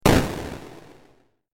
دانلود آهنگ تانک 9 از افکت صوتی حمل و نقل
جلوه های صوتی
دانلود صدای تانک 9 از ساعد نیوز با لینک مستقیم و کیفیت بالا